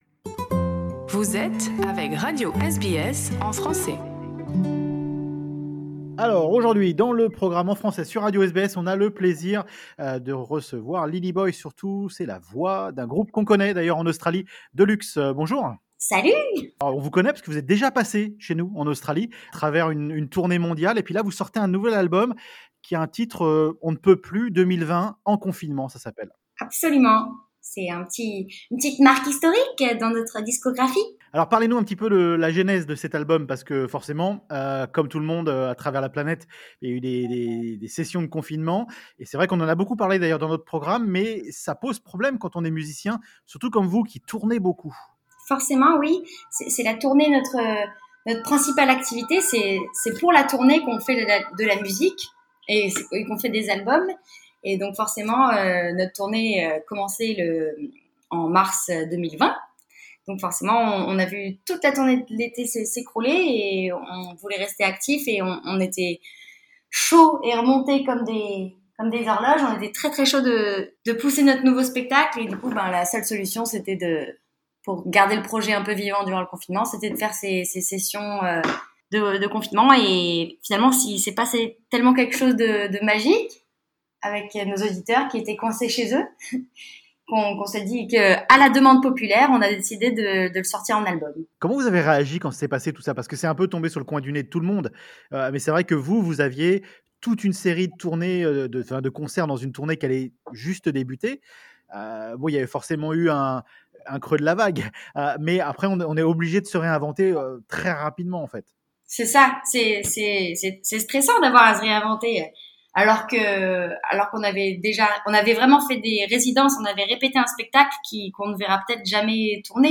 french_music_deluxe2020.mp3